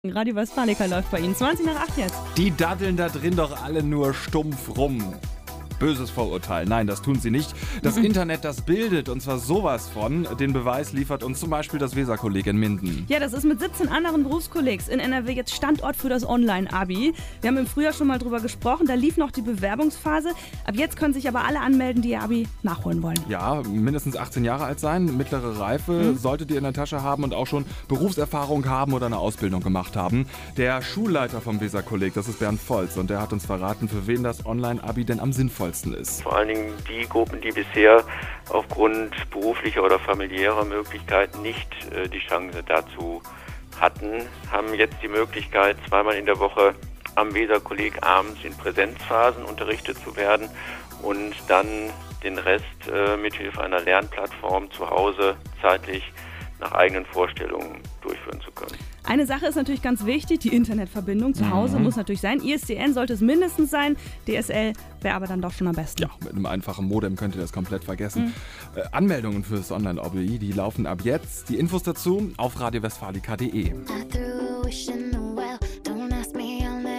Letzte Woche berichtete auch Radio Westfalica darüber.